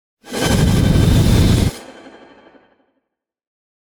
mandrake fvtt13data/Data/modules/psfx/library/ranged-magic/generic/missile/001